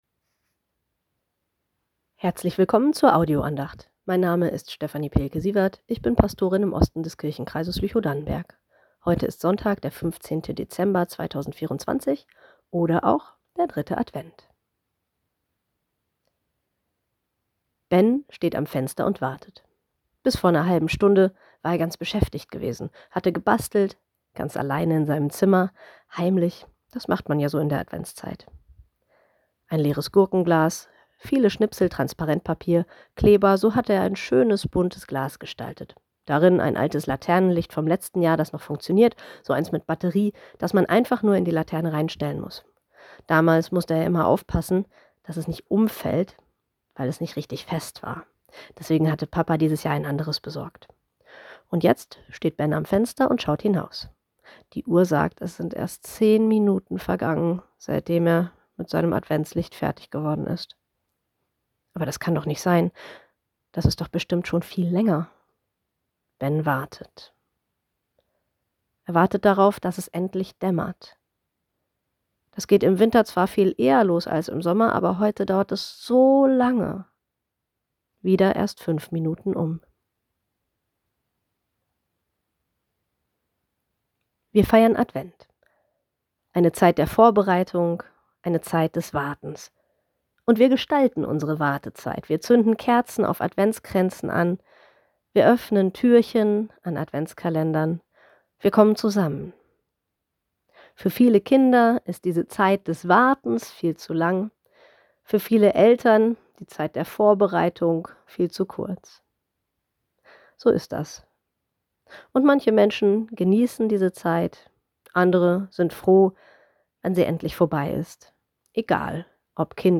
warten und vorbereiten ~ Telefon-Andachten des ev.-luth.